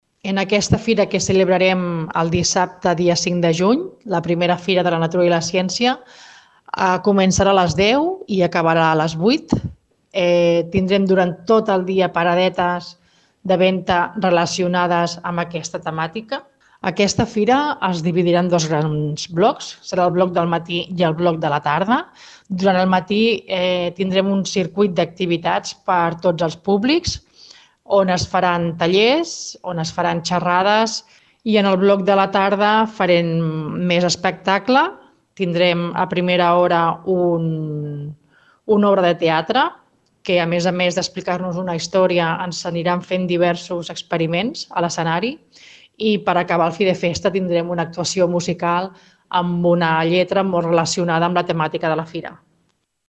Pot participar qualsevol persona física o jurídica que vengui o mostri productes relacionats amb la temàtica de la Natura i la Ciència (artesania amb producte natural, alimentació natural, llibreries que portin llibres relacionats amb la ciència i la natura, joguines especialitzades (experiments, natura, física,…) i/o el desenvolupament sostenible. Ho explica la regidora de medi ambient, Nàdia Cantero.